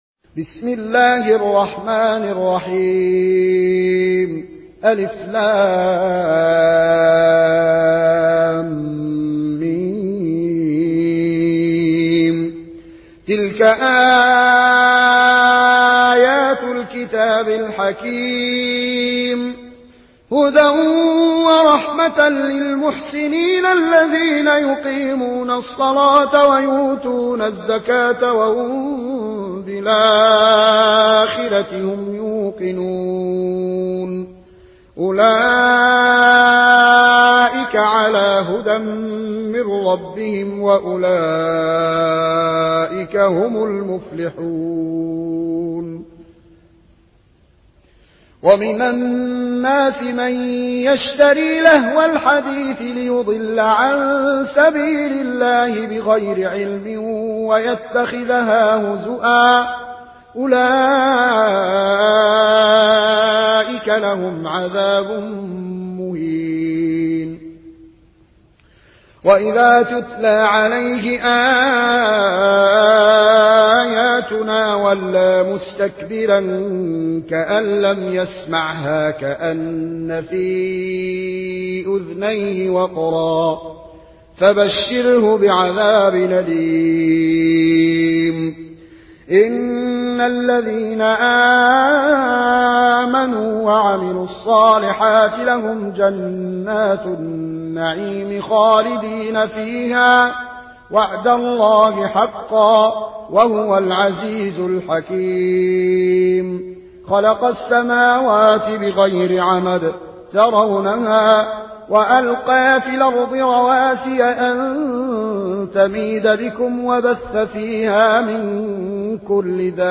Riwayat Warsh from Nafi